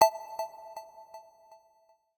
UIClick_Long Echo Short Attack 01.wav